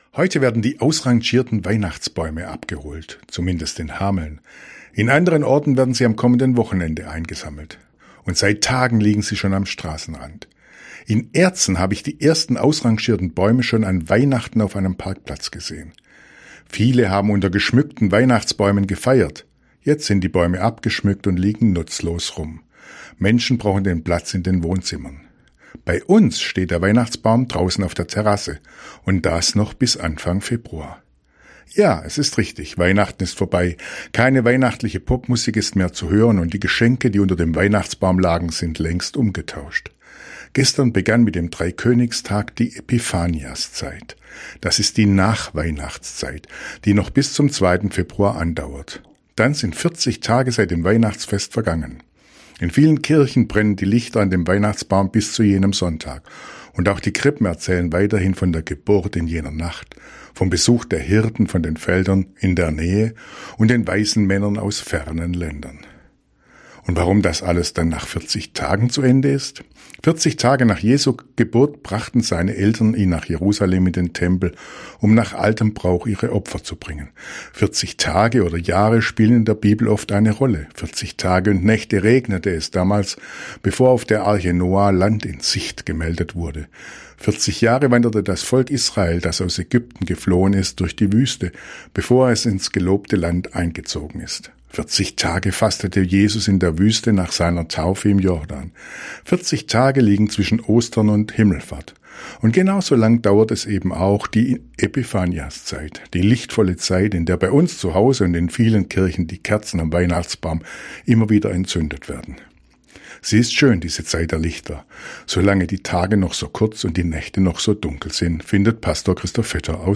Radioandacht vom 7. Januar